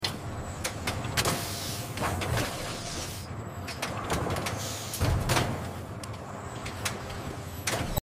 Giant Camel in the Automatic Butcher machine